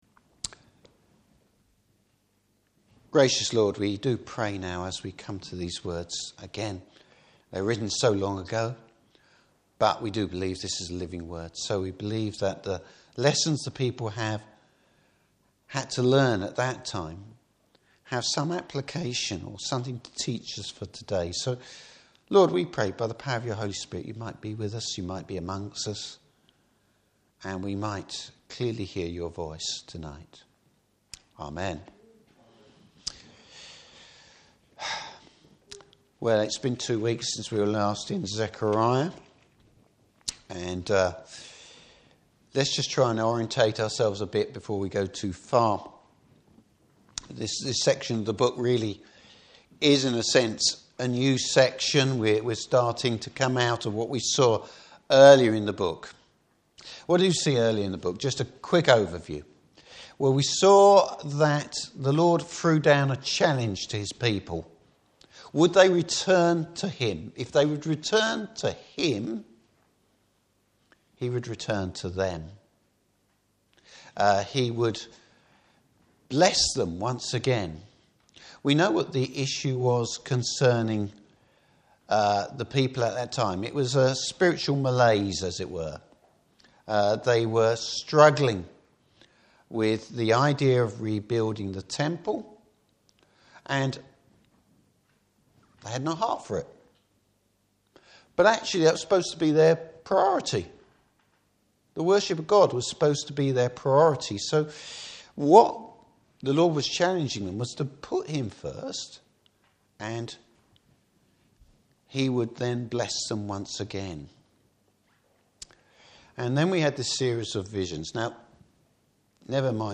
Service Type: Evening Service There’s a blessing for God’s people who model his character.